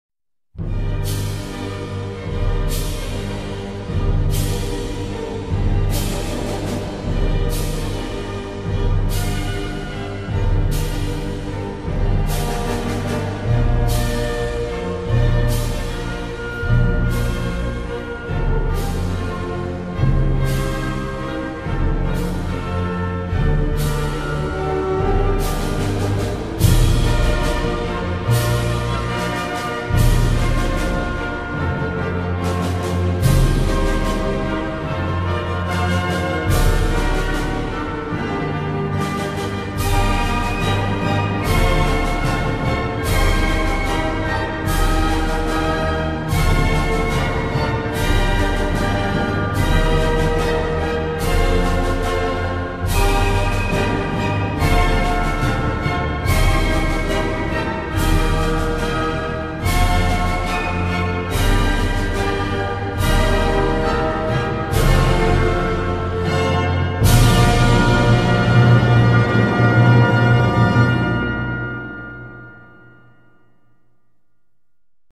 в инструментальном исполнении